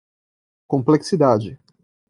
Pronúnciase como (IPA) /kõ.plek.siˈda.d͡ʒi/